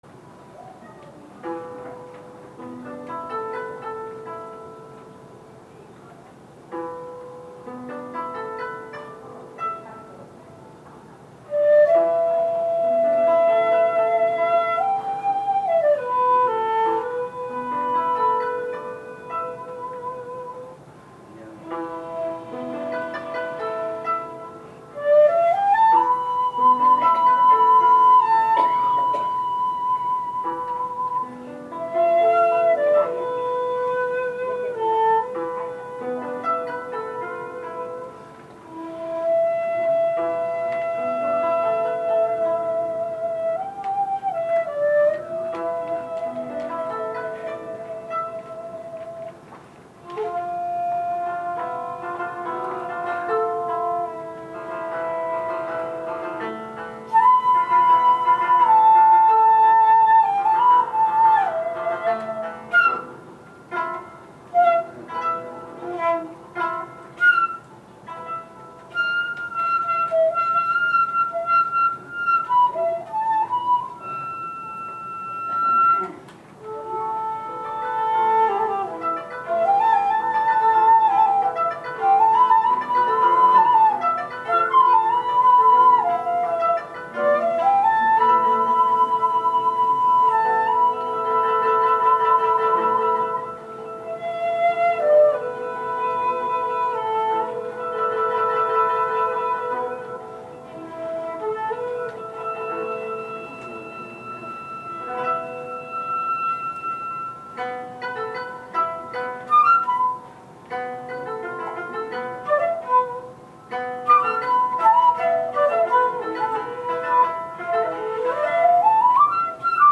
私の一尺六寸管七孔（玉水銘）は普通に吹いて440HｚぐらいなのですがCDの箏はそれよりも２，３Hzも高めのようなのです。 ハローと吹きますと即、「尺八、低い！
しかし、このカラオケの箏はそんな基本的なお調弦ができていないみたいで、響きが美しくないのです。
最初の箏のゆったりした細かい音符が変にルバート（音の伸縮）されているので、箏を聴いて吹き始めると遅すぎる吹き方になり、合わなくなるのです。